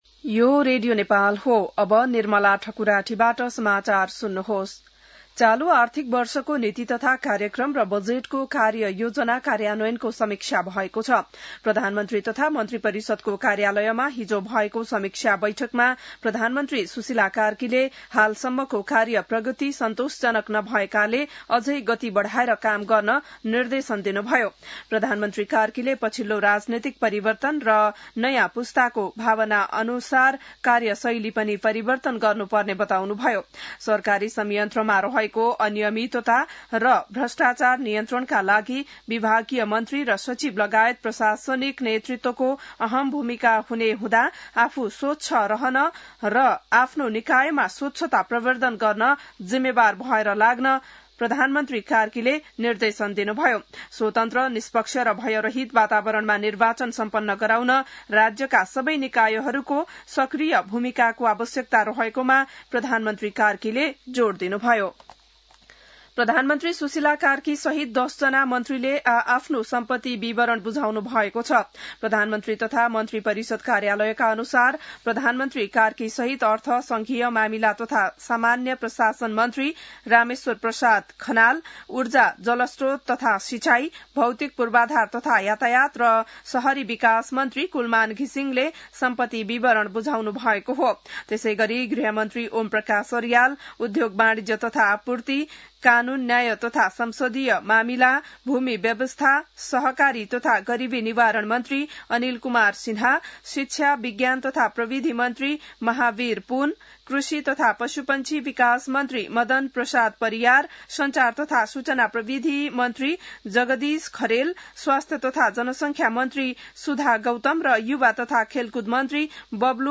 बिहान ११ बजेको नेपाली समाचार : २९ कार्तिक , २०८२